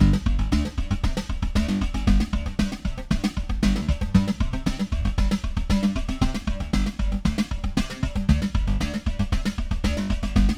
RESO BEAT